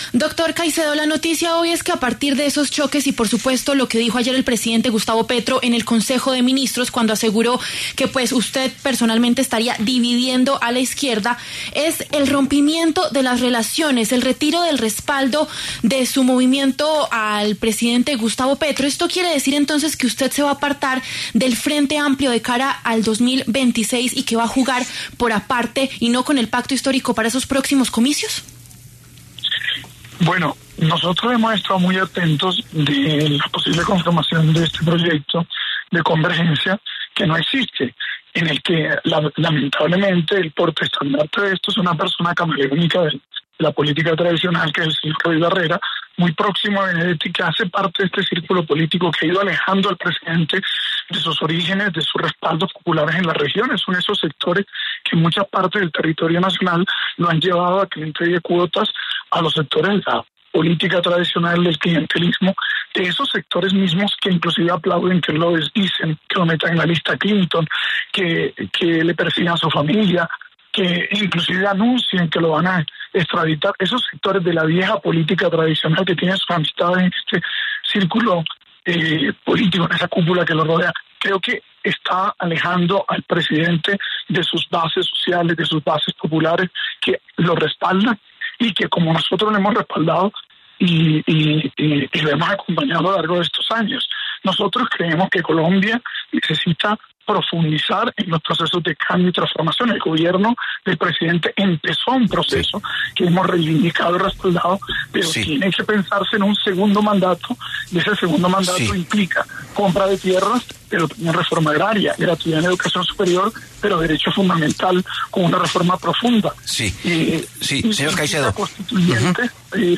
Este miércoles, 26 de noviembre, habló en los micrófonos de La W, el precandidato presidencial y jefe político del movimiento Fuerza Ciudadana, Carlos Caicedo, quien se refirió a un círculo político que estaría “alejando” al presidente Gustavo Petro “de sus bases sociales”.